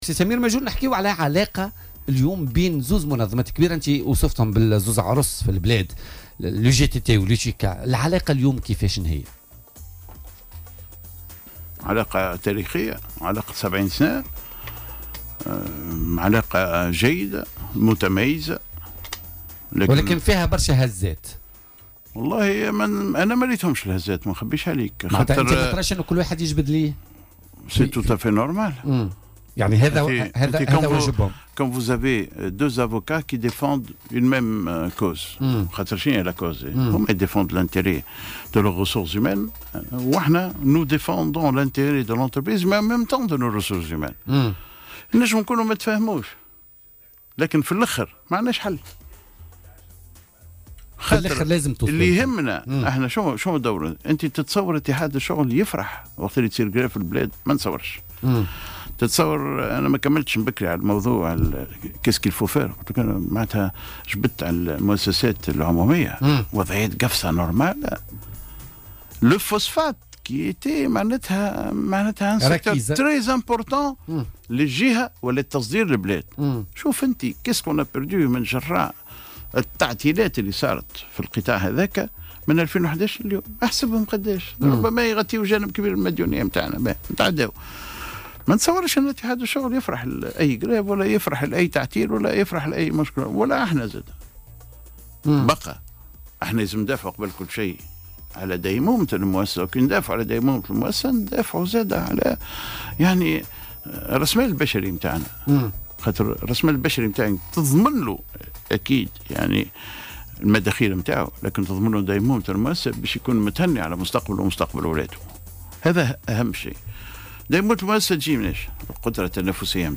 Le président de l'Union Tunisienne de l'industrie, du commerce et de l'artisanat (UTICA), Samir Majoul, était l'invité ce jeudi de l'émission Politica sur Jawhara FM.